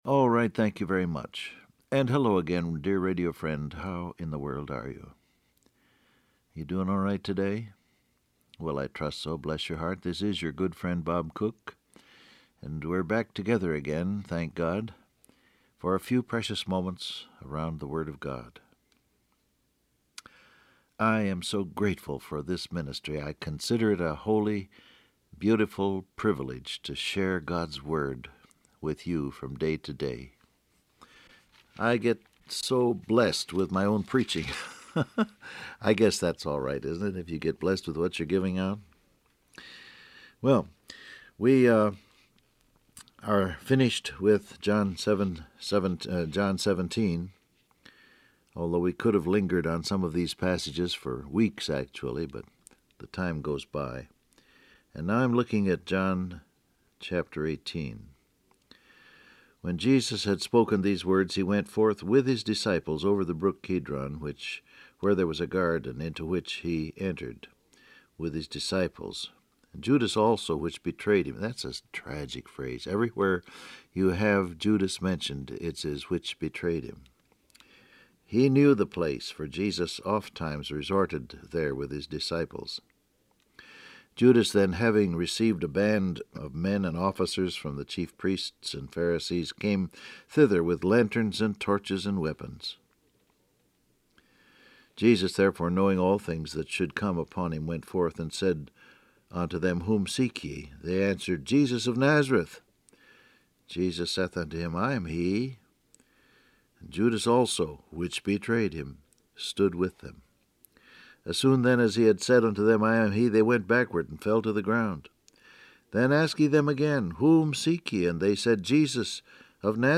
Download Audio Print Broadcast #6961 Scripture: John 18:1-16 Topics: Betrayed , He Knows , Trust Jesus , Denied Him Transcript Facebook Twitter WhatsApp Alright, thank you very much.